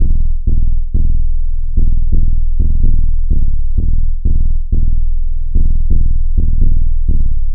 • Techno Sub Bass Melodic.wav
Techno_Sub_Bass_Melodic_1__v8N.wav